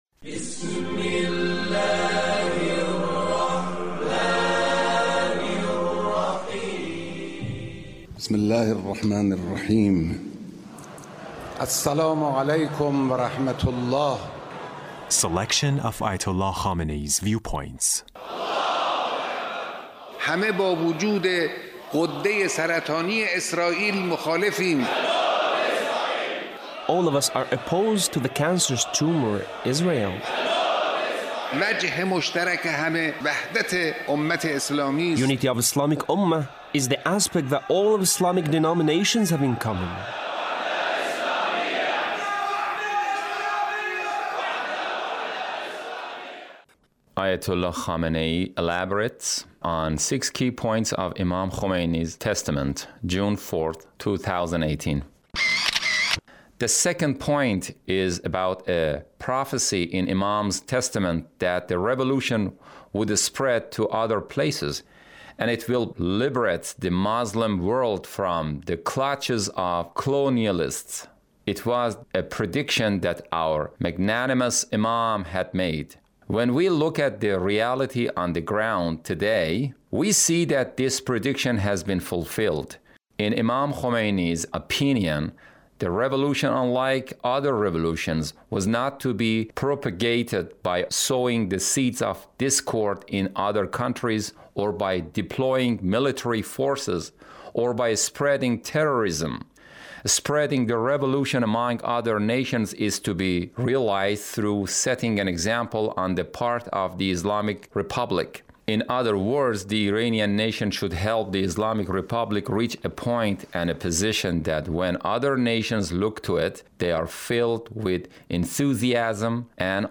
Leader's Speech about the Imam Khomeini